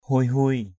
/hʊuɪ-hʊuɪ/ ~ /huɪ-huɪ/ (t.) hiu hiu. angin yuk huei-huei az{N y~K h&]h&] gió thổi hiu hiu.
huei-huei.mp3